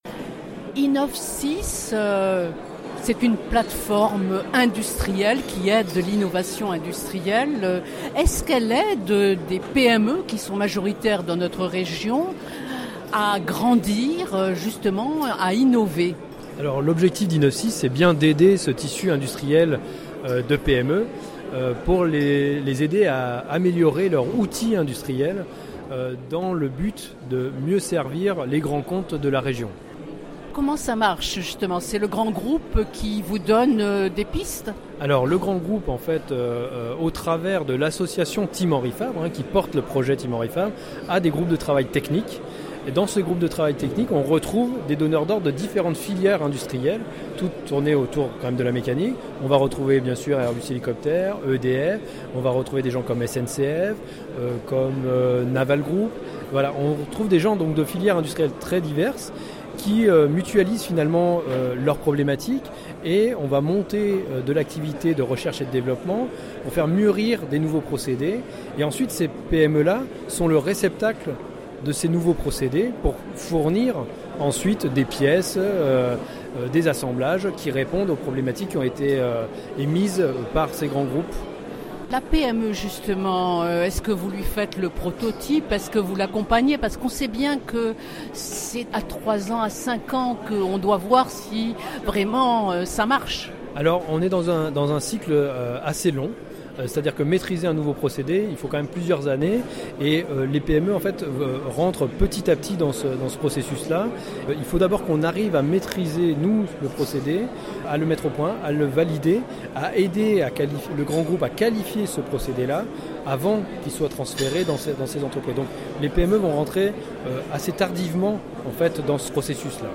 Il présente l’objectif de cette plateforme d’innovation industrielle, en direction des PME,TPE pour «améliorer leur outil industriel dans le but de mieux servir les grands comptes de la région».Entretien.